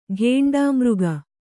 ♪ ghēṇḍā mřga